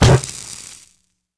rifle_hit_card1.wav